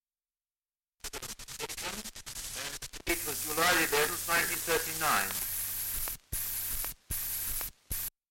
Dialect recording in Acomb, Northumberland
78 r.p.m., cellulose nitrate on aluminium